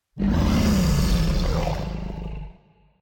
growl3.ogg